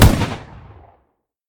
gun-turret-end-1.ogg